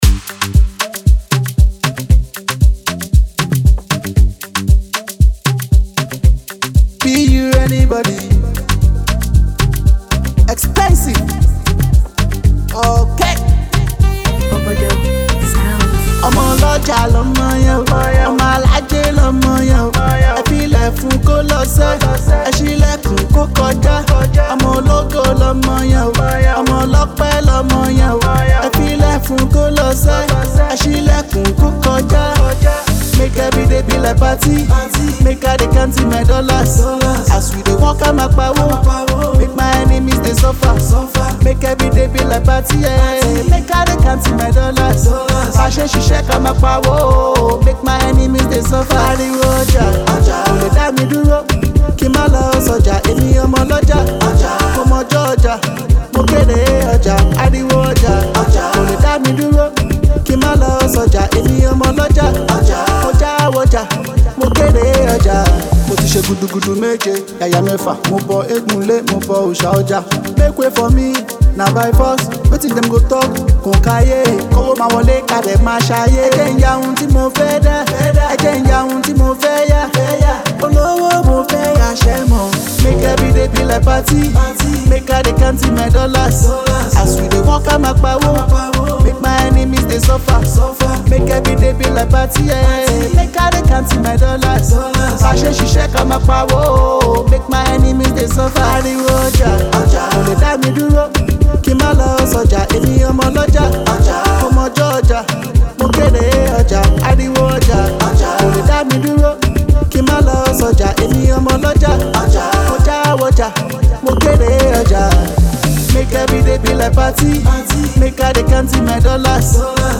With catchy hooks and relatable themes